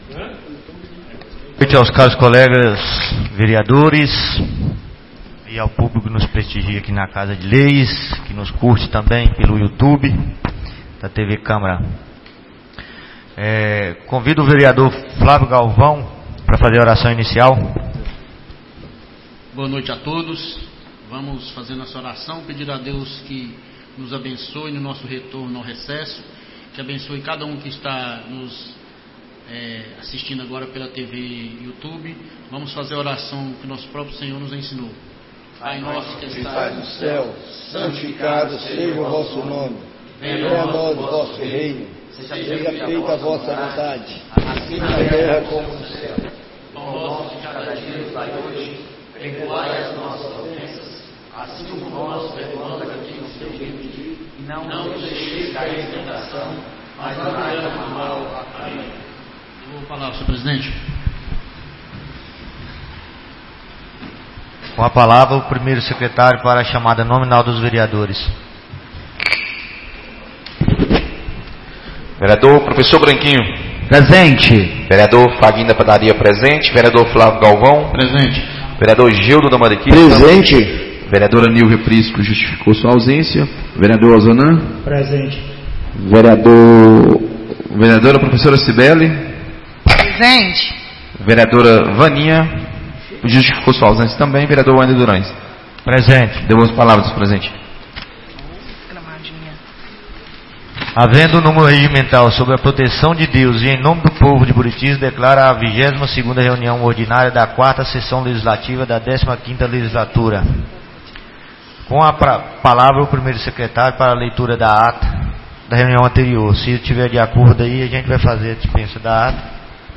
22ª Reunião Ordinária da 4ª Sessão Legislativa da 15ª Legislatura - 05-08-24